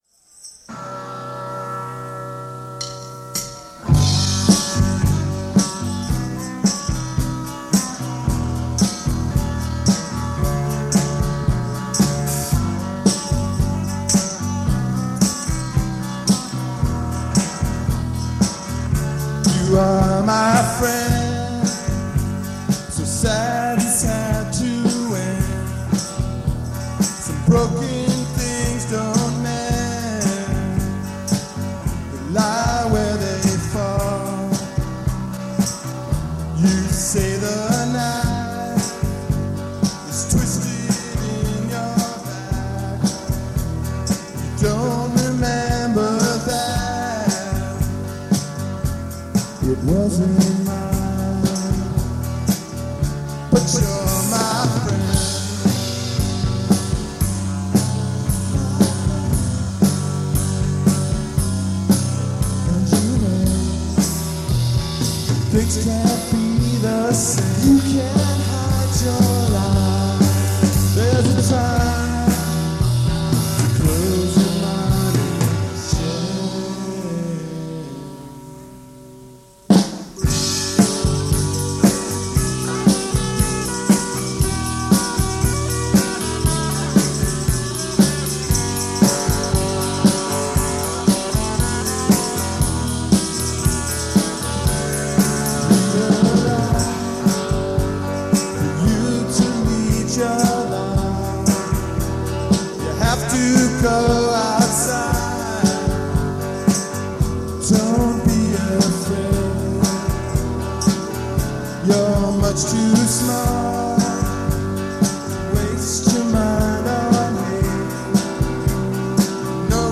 live at Keystone in Palo Alto, California